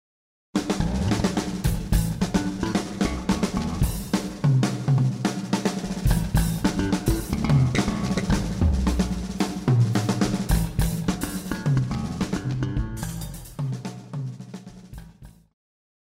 爵士
套鼓(架子鼓)
乐团
演奏曲
现代爵士
独奏与伴奏
有节拍器
将「鼓」和「贝斯」分轨录制，
节奏乐器间的对话也别有一番风格！